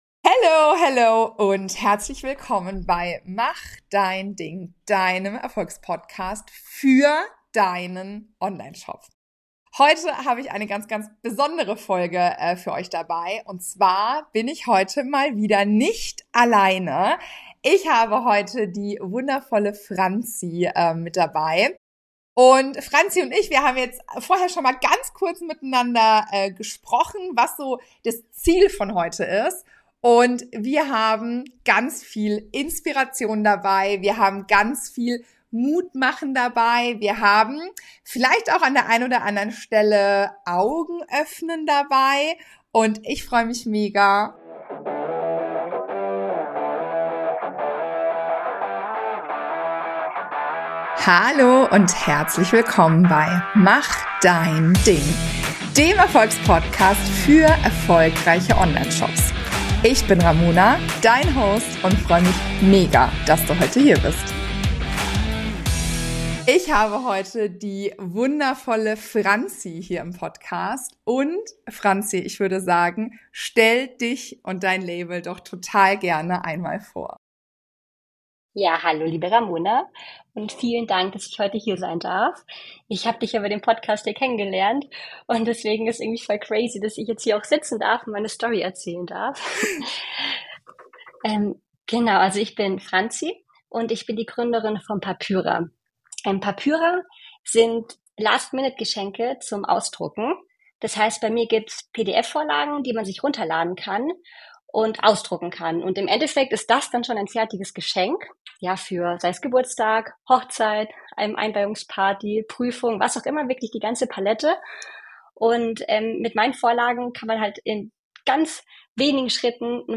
Ein unglaublicher Shop-Erfolg ohne Social Media: Ein Interview